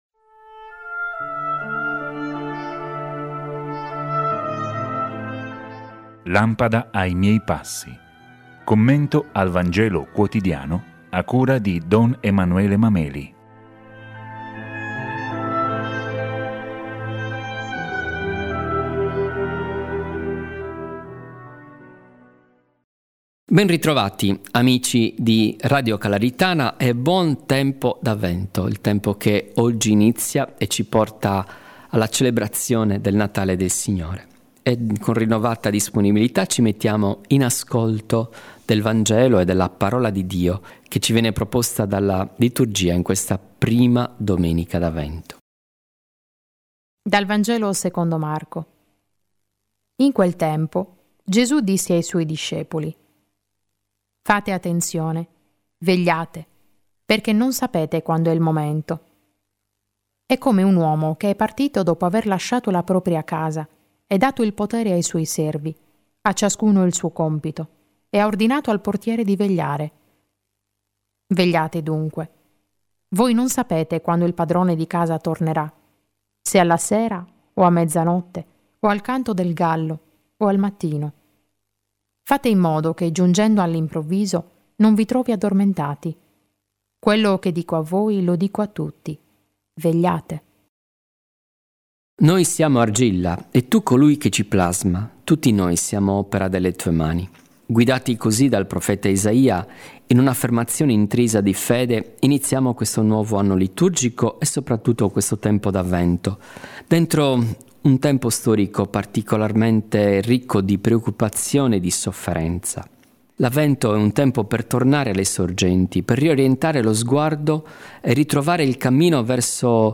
Commento al Vangelo quotidiano
A cura di sacerdoti, diaconi e religiosi della Diocesi di Cagliari